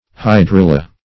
hydrilla - definition of hydrilla - synonyms, pronunciation, spelling from Free Dictionary